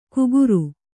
♪ kuguru